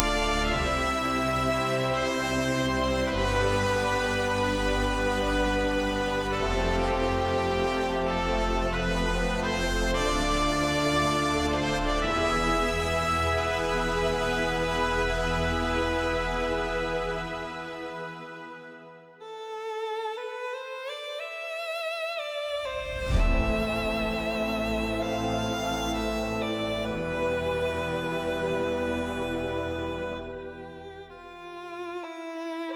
Generates high-fidelity music at 48kHz in stereo format based on textual prompts.
• 48kHz stereo audio output
"prompt": "A cinematic orchestral piece with epic brass swells, thunderous timpani rolls and soaring string melodies"